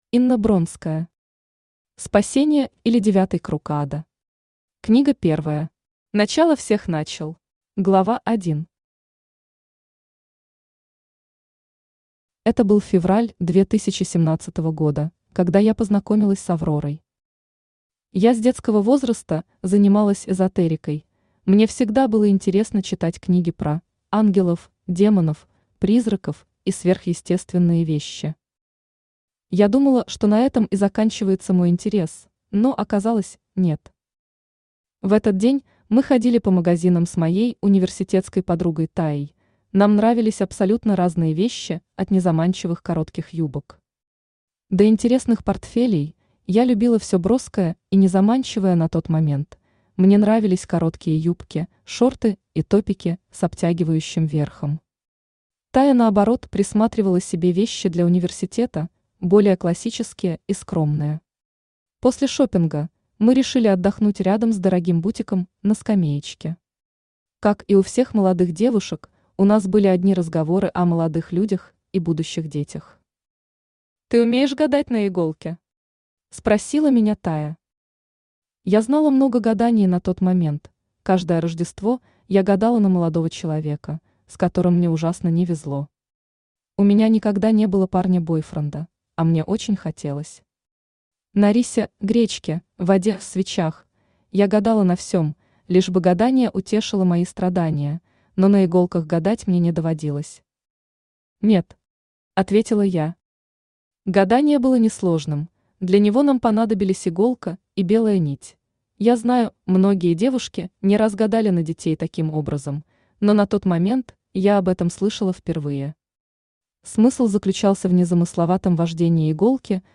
Начало всех начал Автор Инна Дмитриевна Бронская Читает аудиокнигу Авточтец ЛитРес.